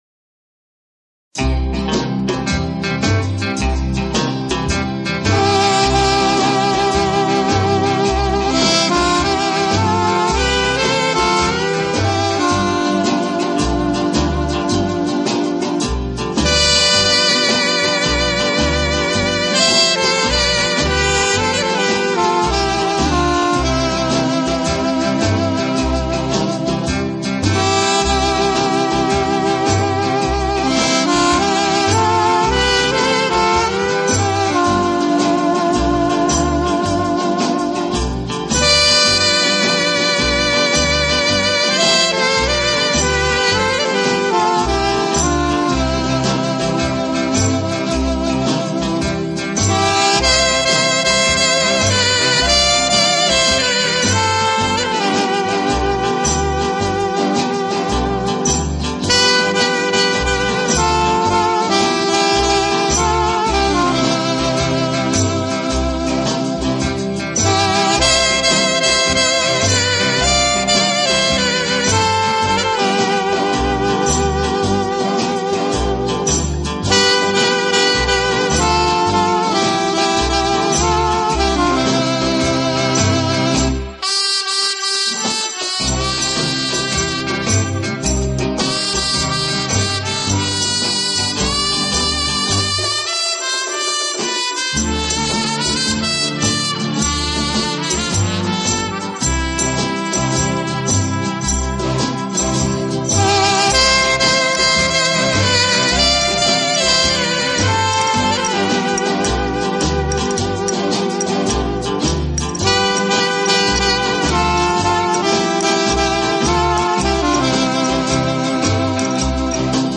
是一位录音艺术家，他录制了大量的轻音乐，器乐曲的专辑，这些专辑在整个60年代